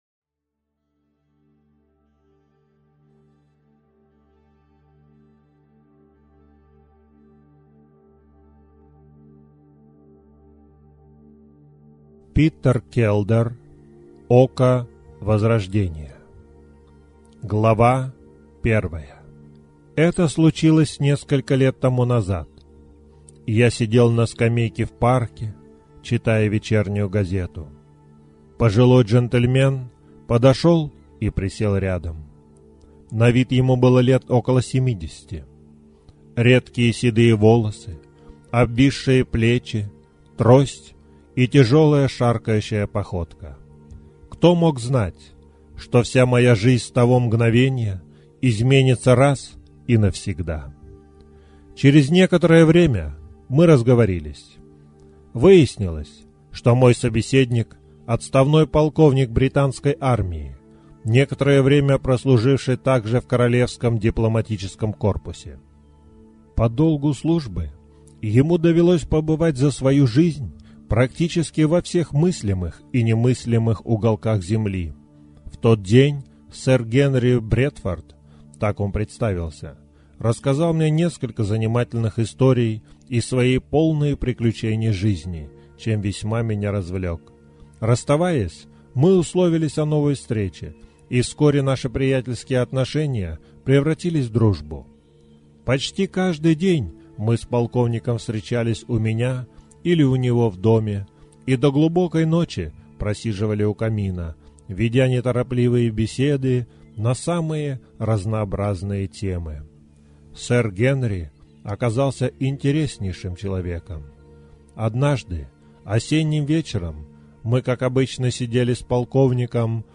Скачано : 1925 раз Аудиокнига Око возрождения Око возрождения часть1 Питер Келдер 1 × Обсуждения Око возрождения часть1